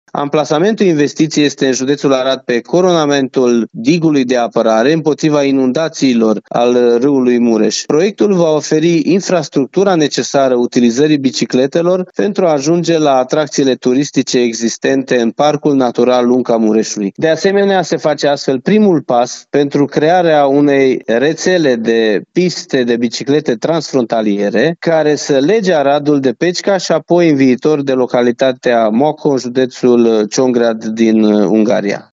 Amenajarea unei piste de biciclete care să lege orașele Arad și Pecica a fost aprobată de Consiliul Județean Arad. Acesta e primul pas pentru crearea unei rețele transfrontaliere de piste de biciclete în județ, spune președintele Consiliului Județean Arad, Iustin Cionca.